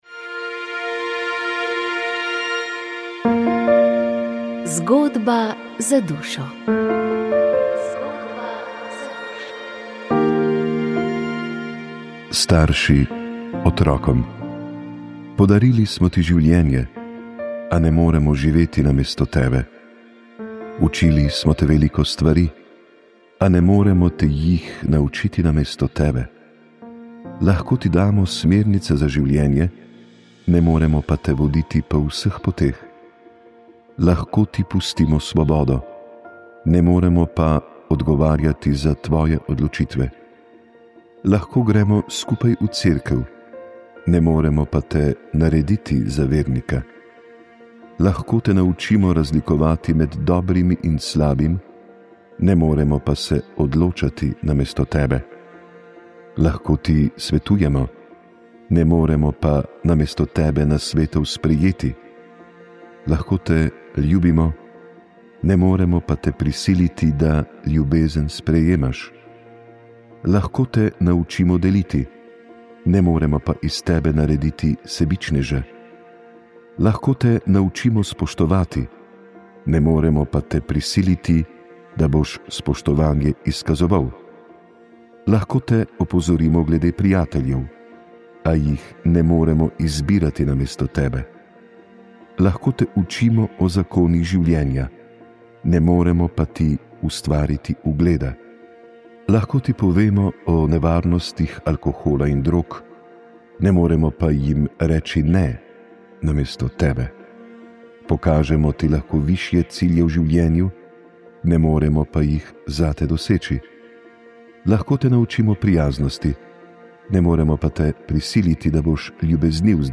Dosegljivo v Arhivu Republike Slovenije.
Posnetek programa Radia Ognjišče dne 27. oktober 2025 ob 05-ih